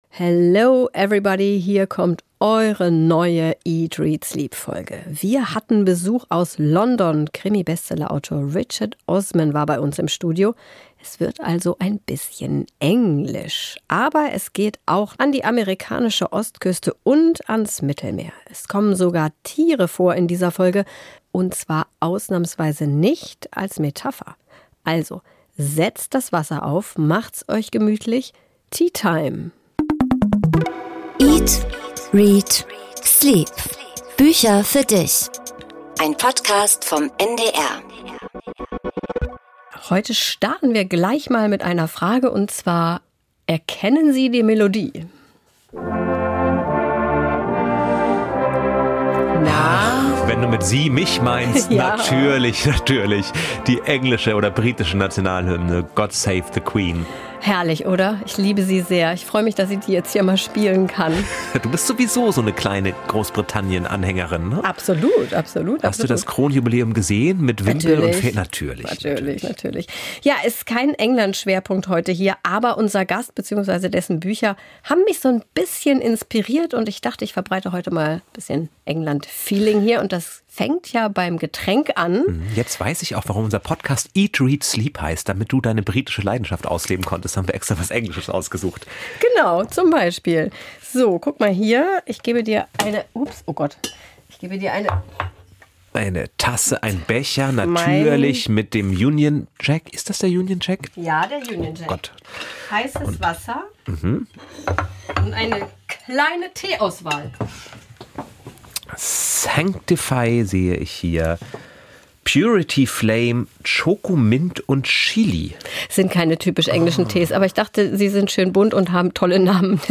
Bei einer Tasse Harmony-Tee sprechen die Hosts mit ihm darüber, wie es ist, plötzlich Steven Spielberg am Telefon zu haben und welche Schauspielerin am besten für die Verfilmung des Donnerstagsmordclubs geeignet wäre.
Interview mit Richard Osman